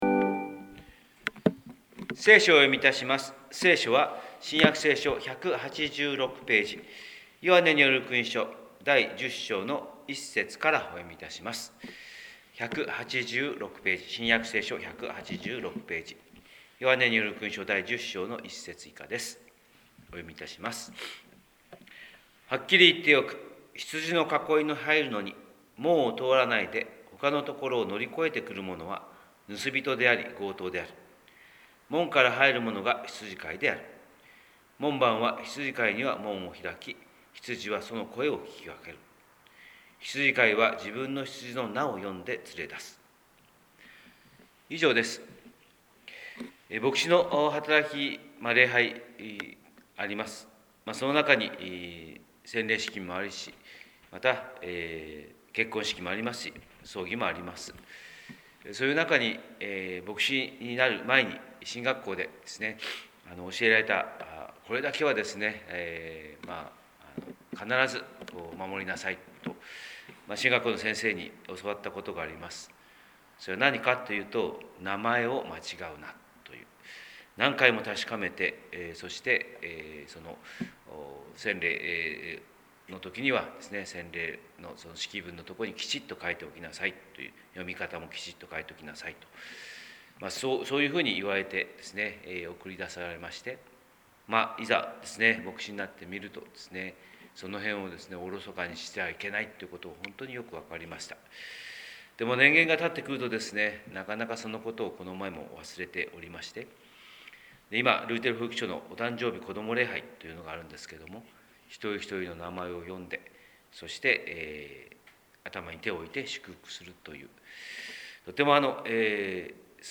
神様の色鉛筆（音声説教）: 広島教会朝礼拝250502